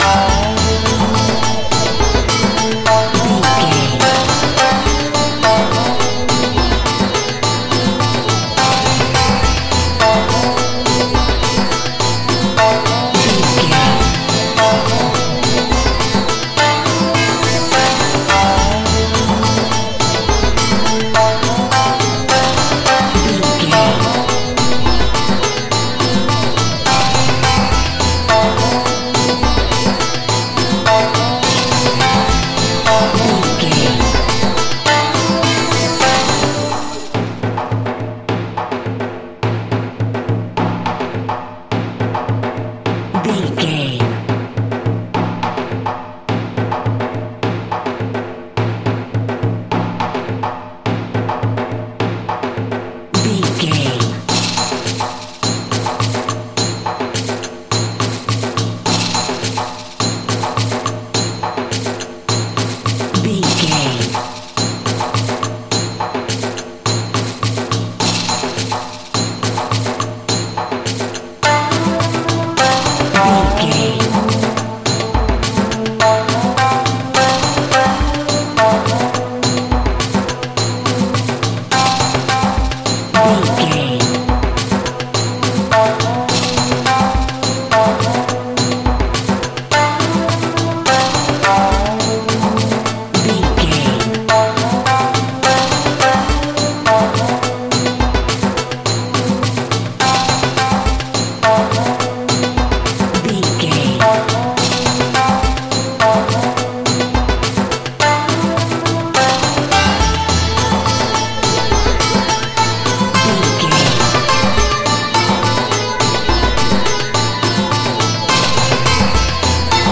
World Horror.
Atonal
tension
ominous
eerie
synthesizer
Synth Pads
atmospheres